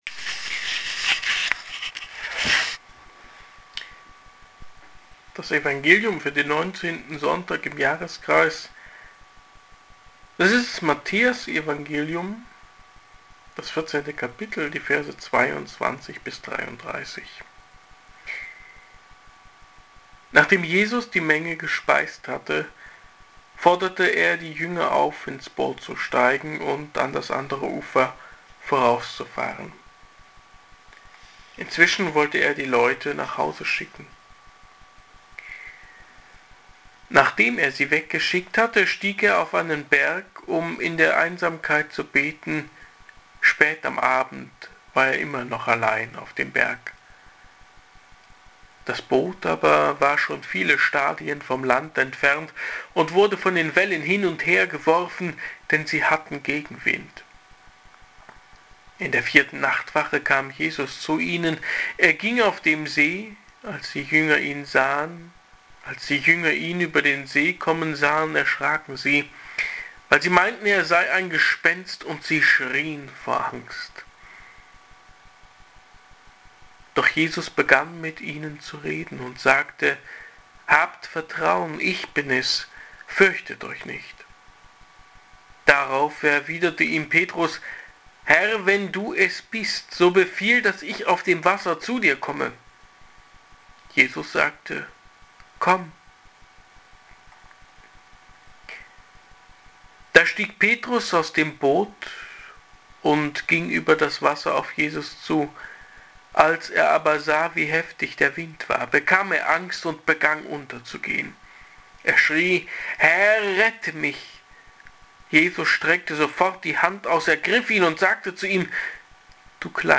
Ausstieg zum Einstieg oder sitzen bleiben und nichts riskieren? Predigt zum 19. So. i. Jkr. i. Lj. A